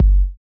Kick Groovin 8.wav